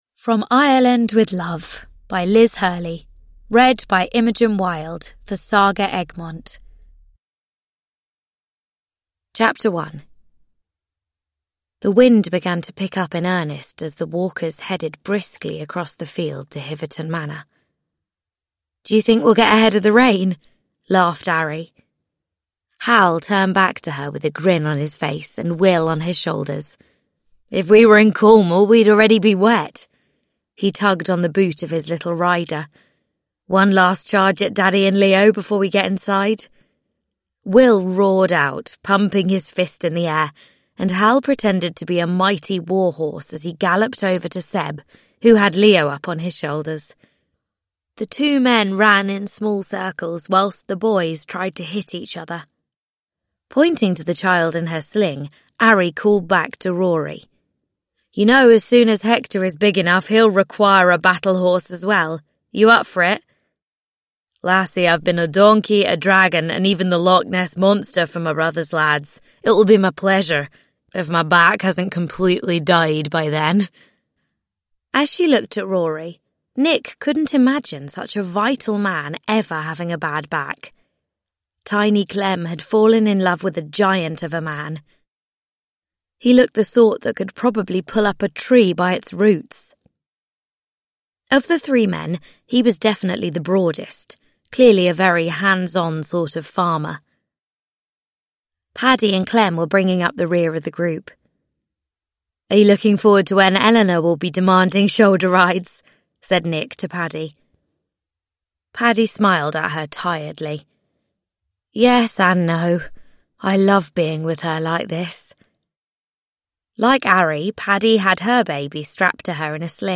From Ireland With Love – Ljudbok